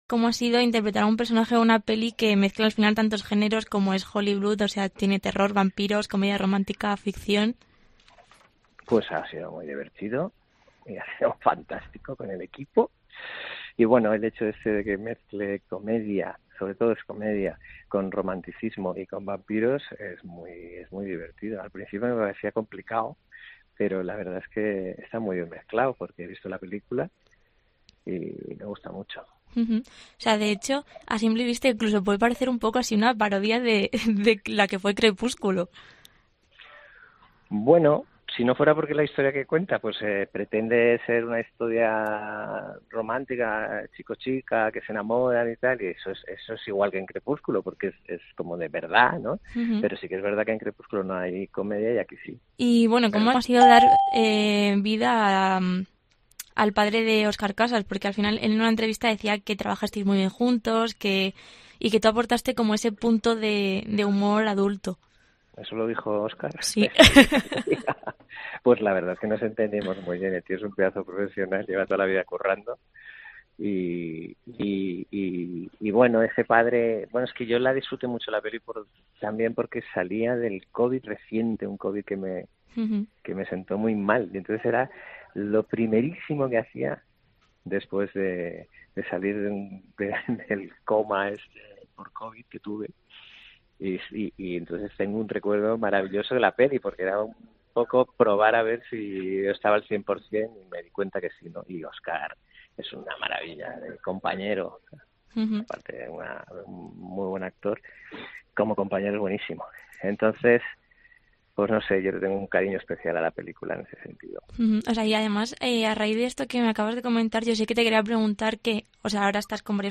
Entrevista completa a Jordi Sánchez por el estreno de 'Hollyblood'